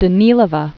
(də-nēlə-və), Alexandra 1904-1997.